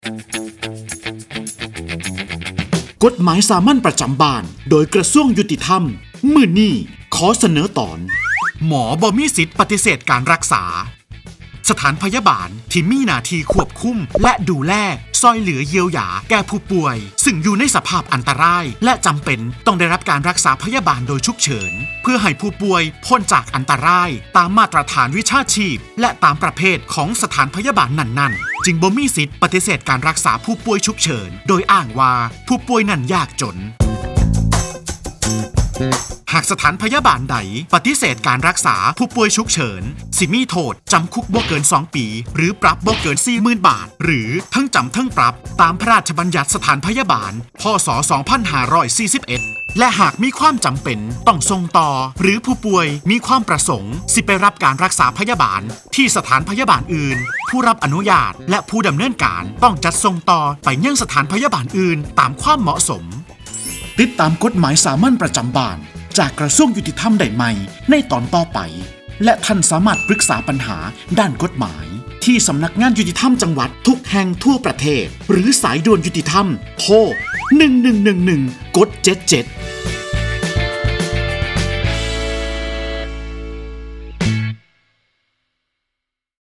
กฎหมายสามัญประจำบ้าน ฉบับภาษาท้องถิ่น ภาคอีสาน ตอนหมอไม่มีสิทธิ์ปฏิเสธการรักษา
ลักษณะของสื่อ :   บรรยาย, คลิปเสียง